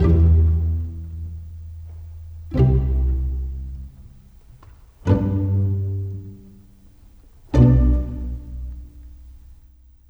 Rock-Pop 09 Pizzicato 02.wav